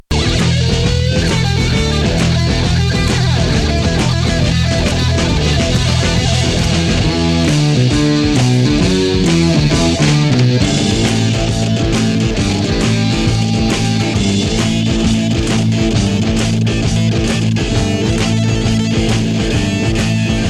Теперь возможно сжатие с максимальным битрейтом 224 Кбит/с при частоте дискретизации 44 кГц.
Качество звука откровенно порадовало (скачать 20-секундные фрагменты, MP3,фрагмент-1, 471 КБ и
• Отличное качество звука в FM-режиме